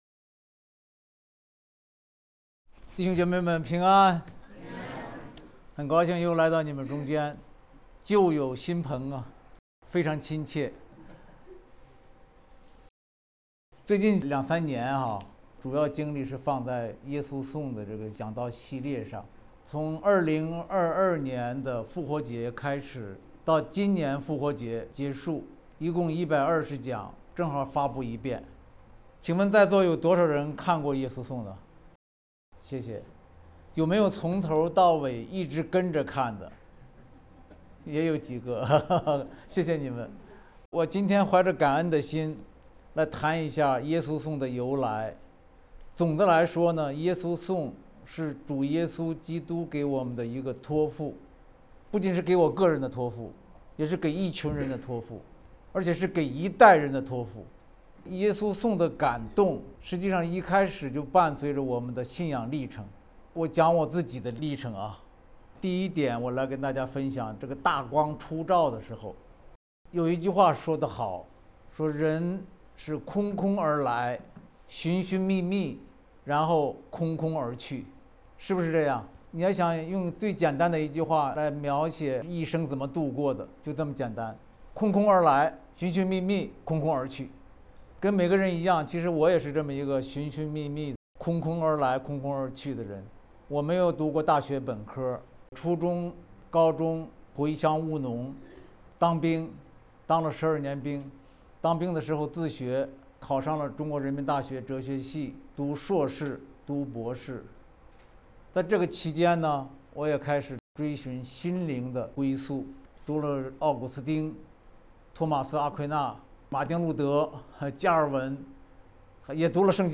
佳音教会主日讲道